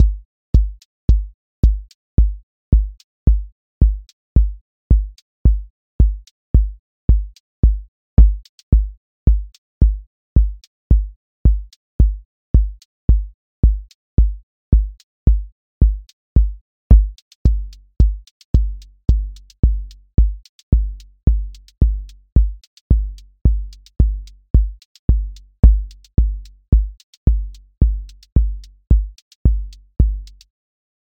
QA Listening Test house Template: four_on_floor
house four on floor 30s
• voice_kick_808
• voice_hat_rimshot
• voice_sub_pulse
• tone_warm_body